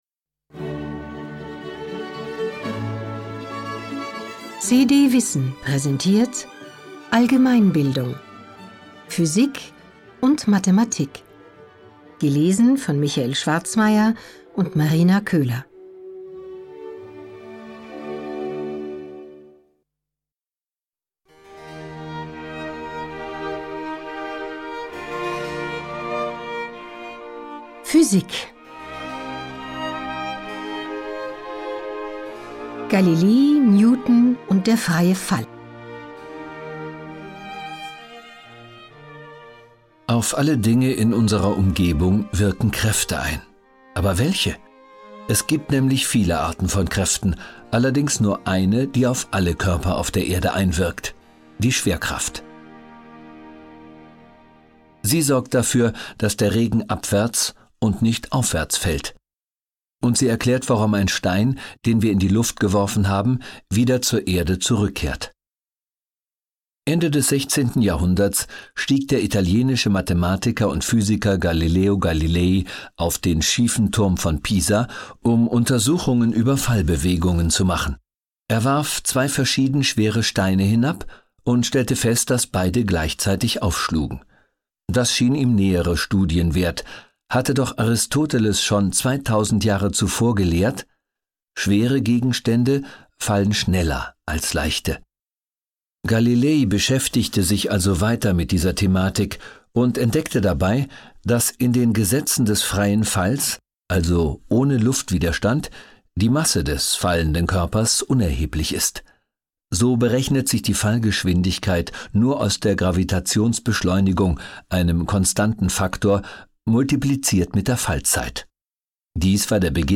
Hörbuch: CD WISSEN - Allgemeinbildung.